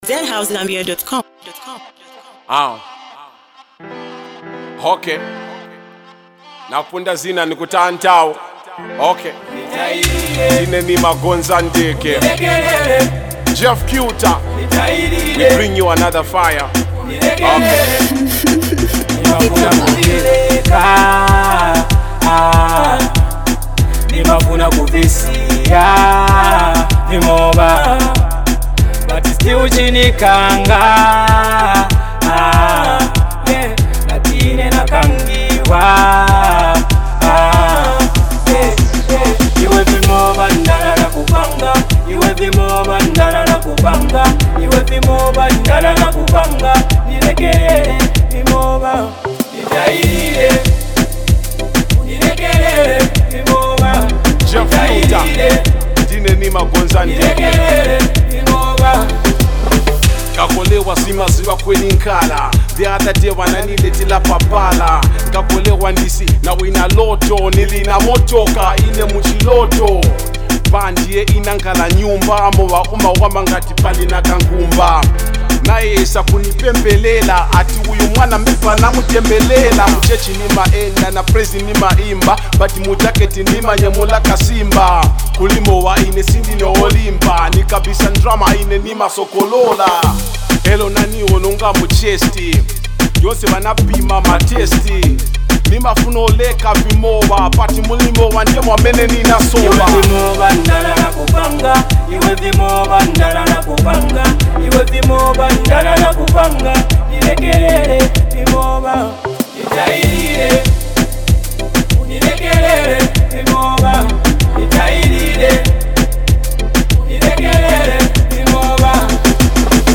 a hard-hitting street anthem